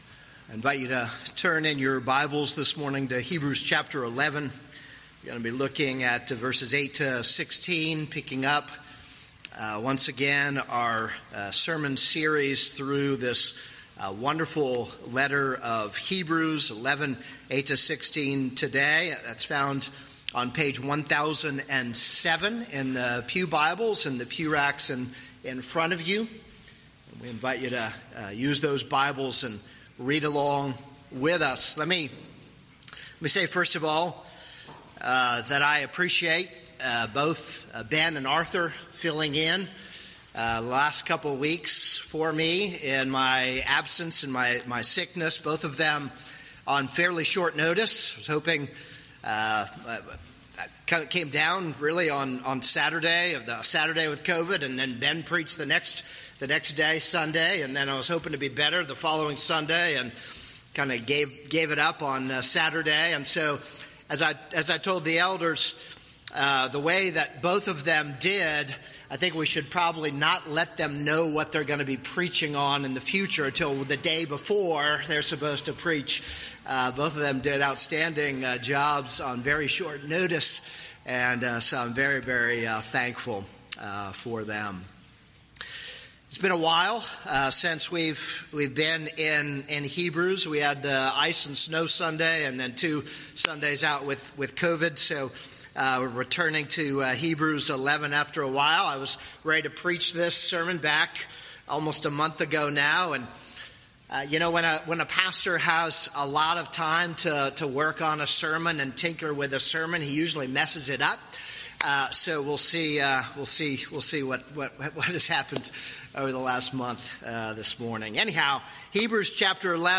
This is a sermon on Hebrews 11:8-16.